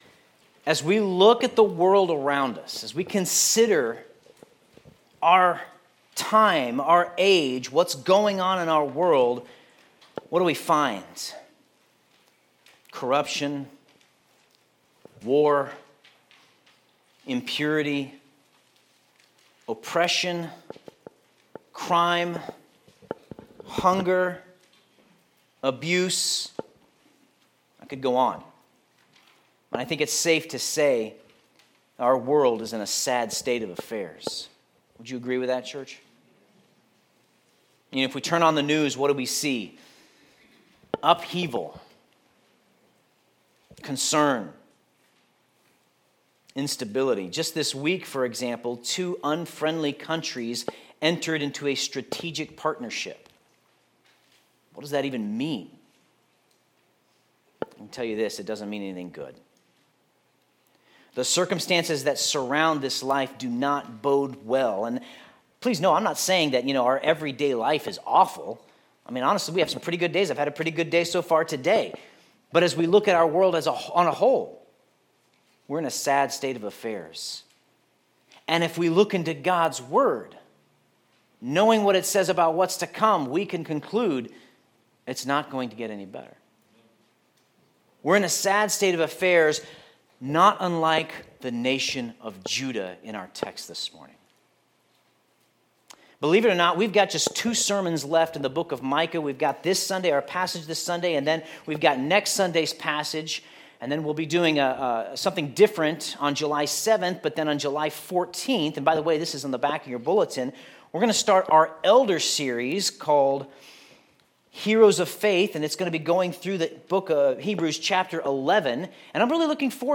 Sermon Notes In Judah, and as now, our world is in a sad state of affairs.